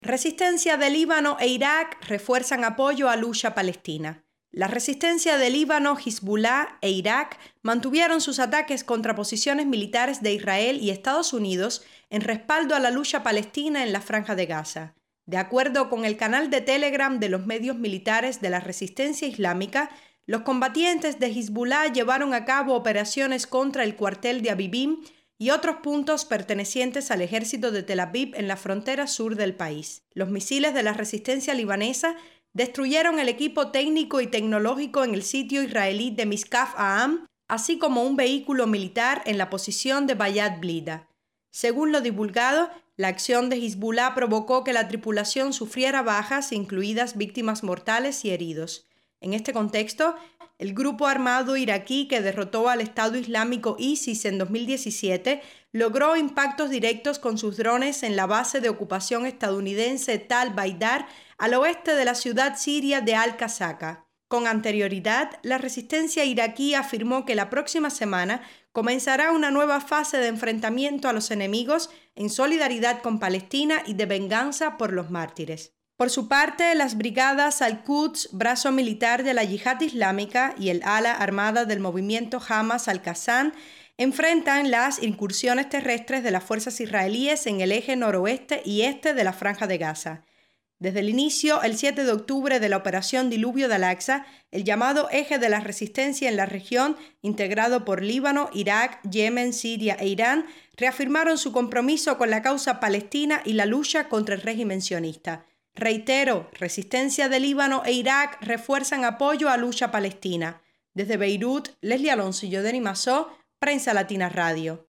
desde Beirut